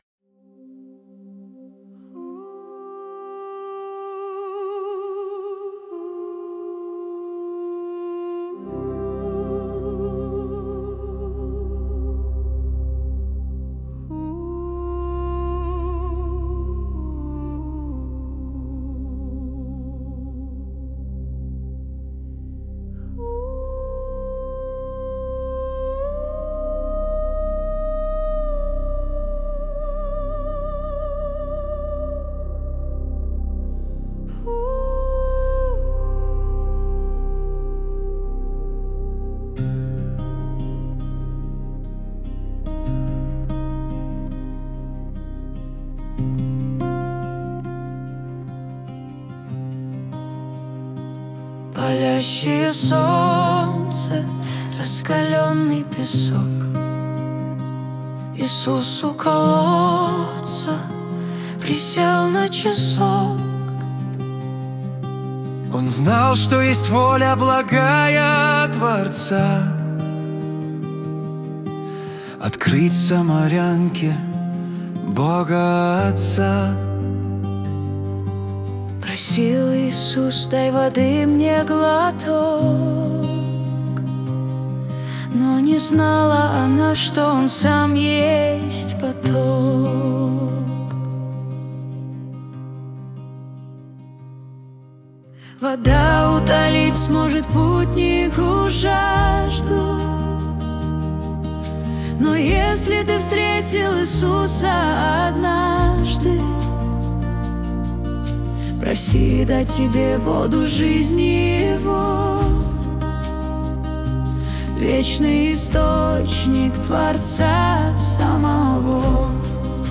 песня ai
266 просмотров 1006 прослушиваний 93 скачивания BPM: 68